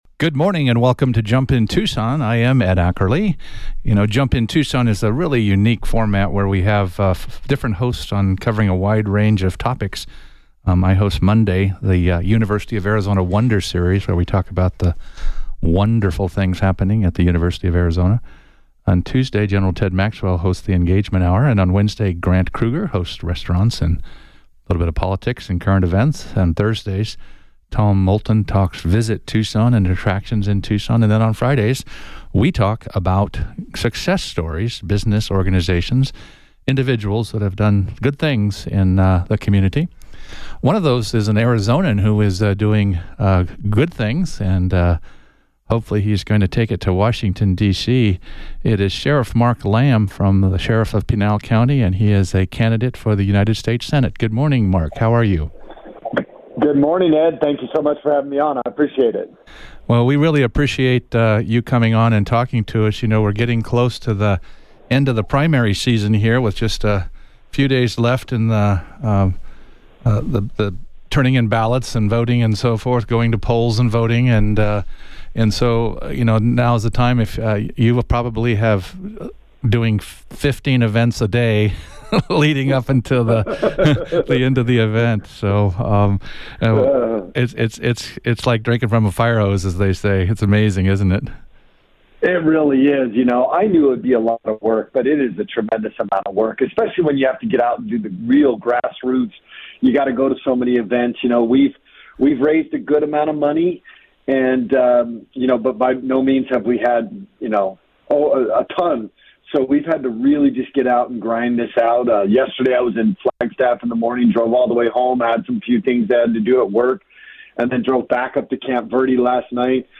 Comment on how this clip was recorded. Based on the 7/19/24 Jump In Tucson episode on KVOI-1030AM in Tucson, AZ.